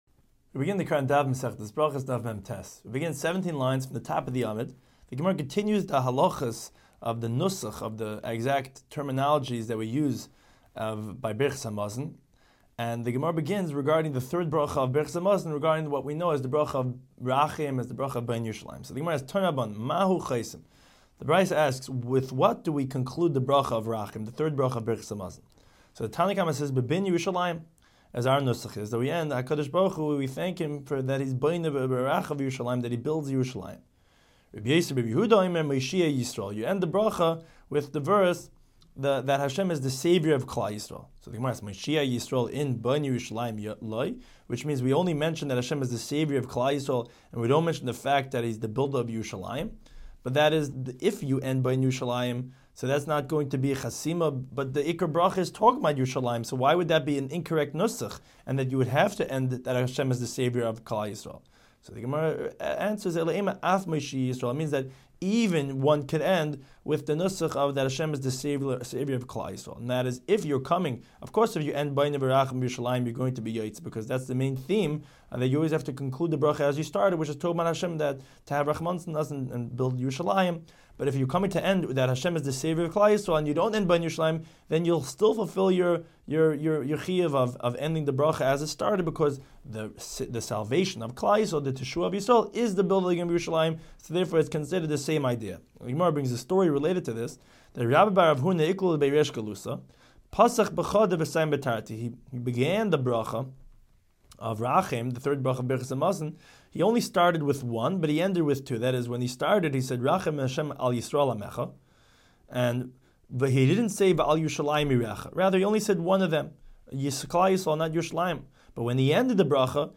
Daf Hachaim Shiur for Berachos 49